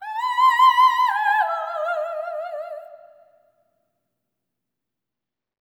OPERATIC10.wav